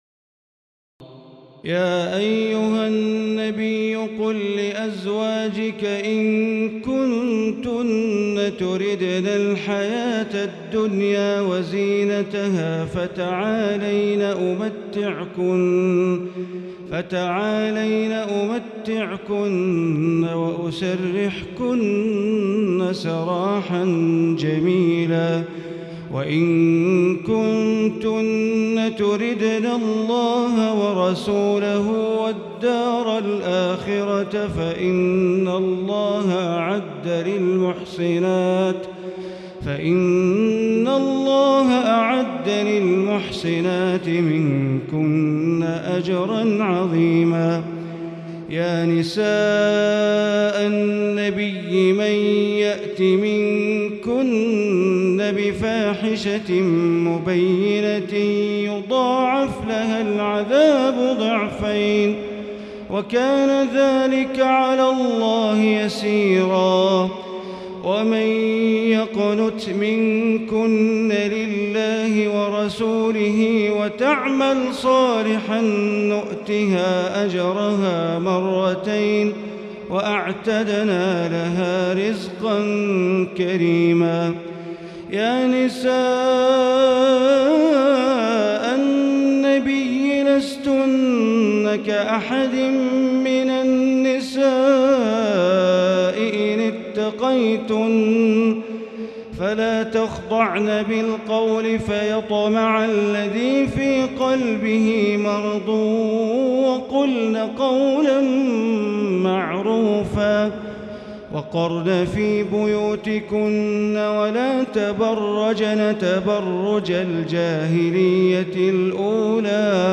تراويح ليلة 21 رمضان 1438هـ من سور الأحزاب (28-73) وسبأ (1-33) Taraweeh 21 st night Ramadan 1438H from Surah Al-Ahzaab and Saba > تراويح الحرم المكي عام 1438 🕋 > التراويح - تلاوات الحرمين